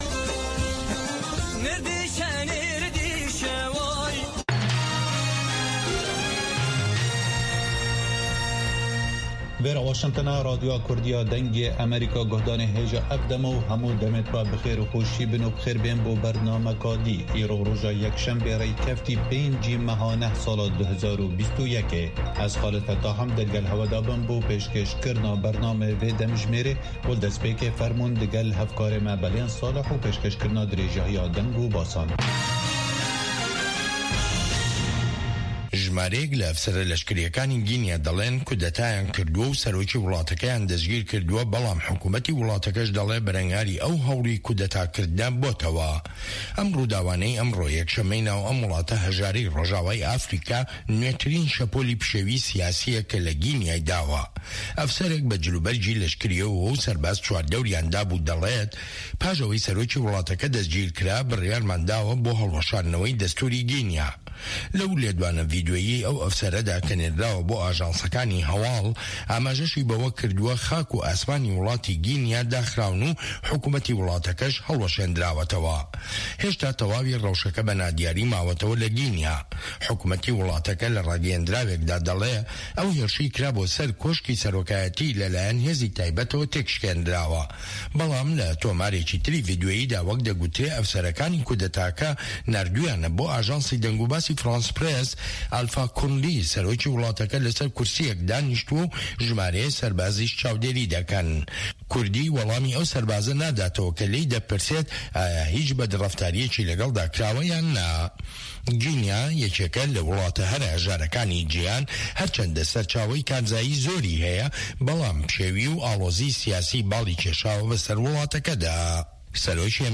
Nûçeyên 3’yê paşnîvro